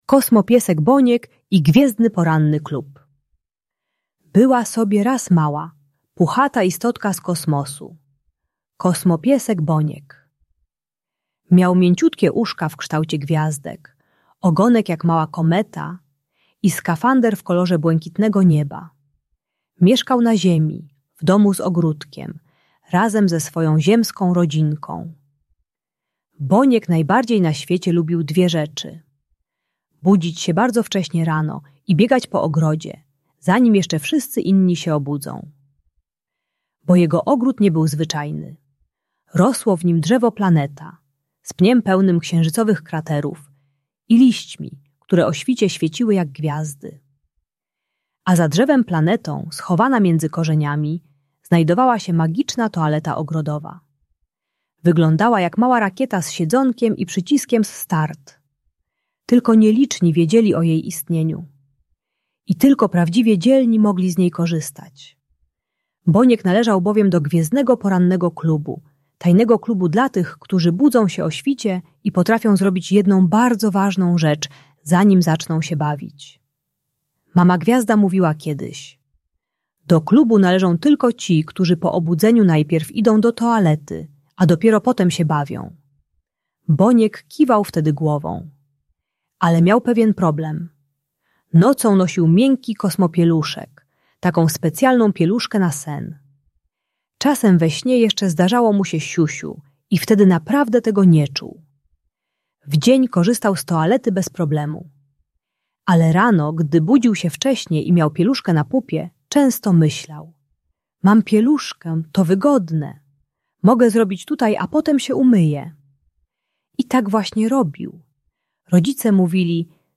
Kosmopiesek Boniek i Gwiezdny Poranny Klub - Trening czystości | Audiobajka